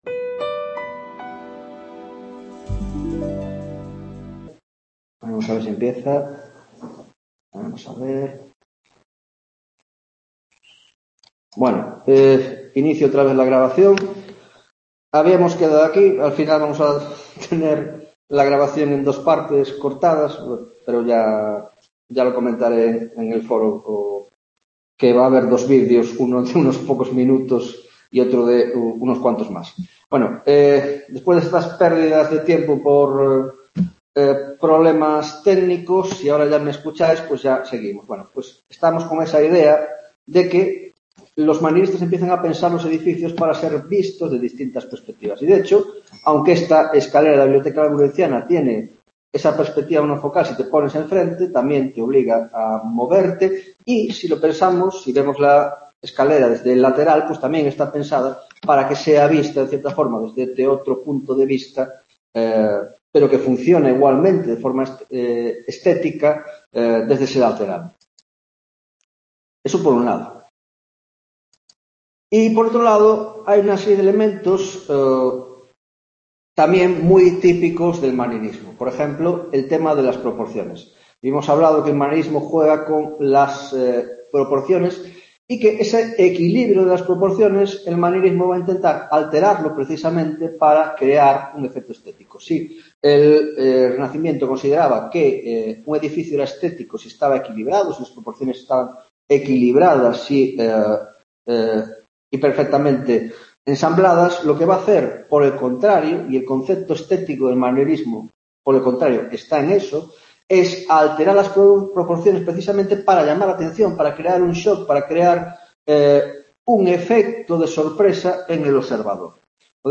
3ª Tutoría de Órdenes y Espacio en la Arquitectura del XV - XVIII - Introducción: La Concepción del Espacio, 3ª Parte